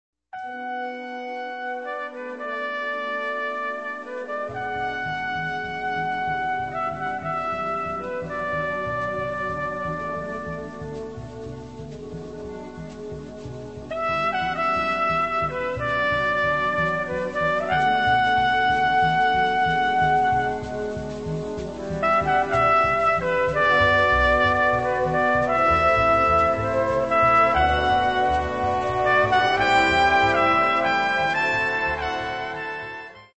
l'Ostinato.
La parte introduttiva è in tonalita di Si minore.